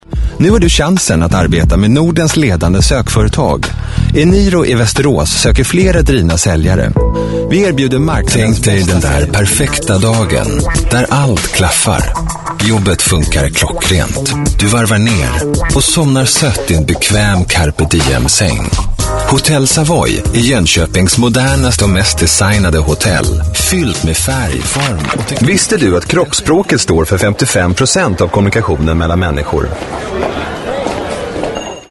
Native speaker Male 30-50 lat
Nagranie lektorskie